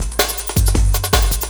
06 LOOP10 -L.wav